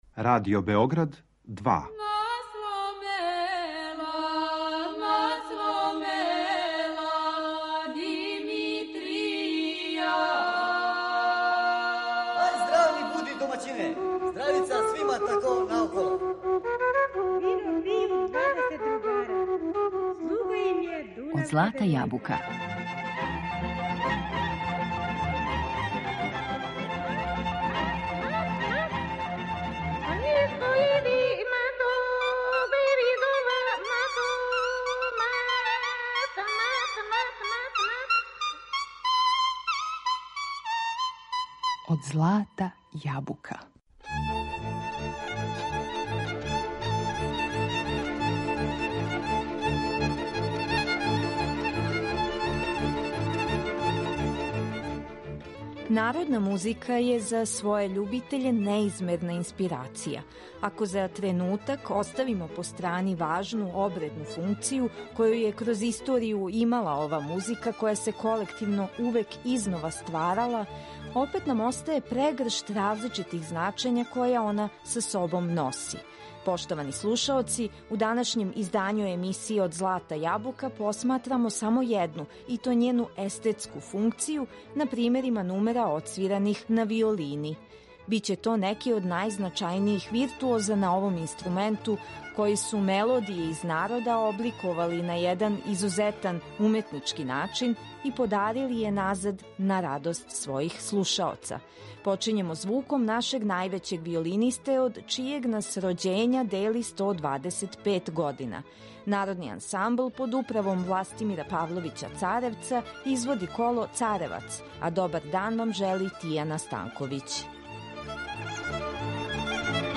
Нумере одсвиране на виолини
У данашњем издању емисије Од злата јабука посматрамо њену естетску функцију на примерима нумера одсвираних на виолини. Биће то неки од најзначајнијих виртуоза на овом инструменту који су мелодије из народа обликовали на један изузетан, уметнички начин и подарили је назад, на радост својих слушаоца.